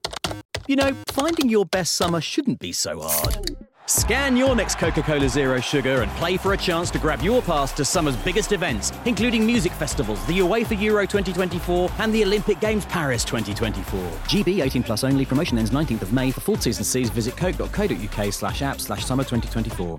40's Neutral/RP,
Friendly/Confident/Natural
Commercial Showreel